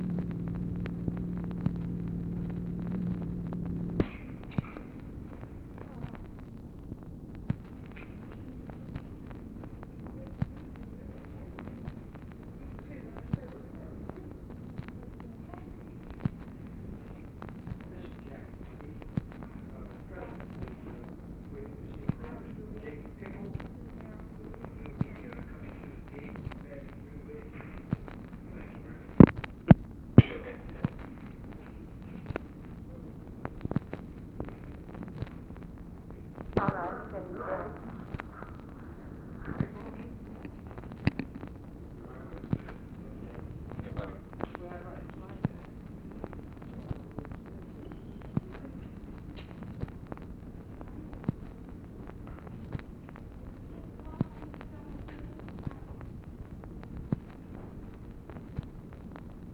OFFICE CONVERSATION, January 6, 1964
Secret White House Tapes